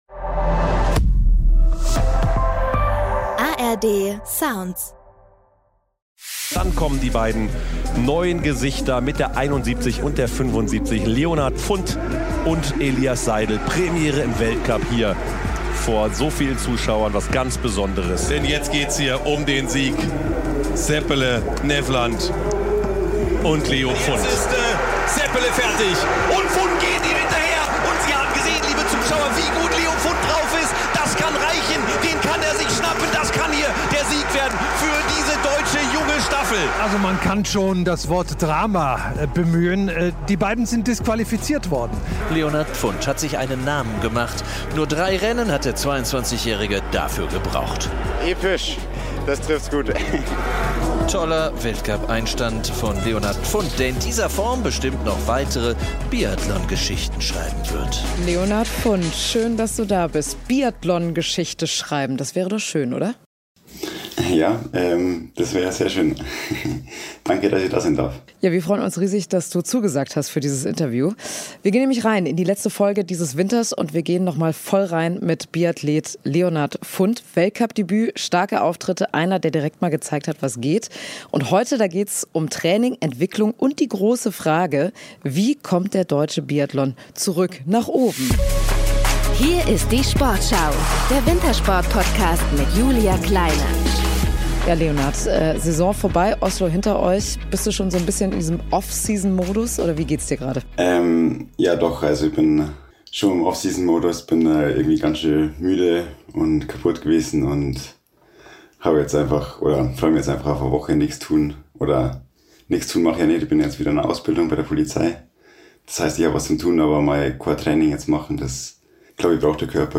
Dazu gibt's im Experten-Talk mit Langlauf-Trainer Axel Teichmann Einblicke in die Trainingswelt auf Topniveau: Wie arbeitet ein Bundestrainer konkret?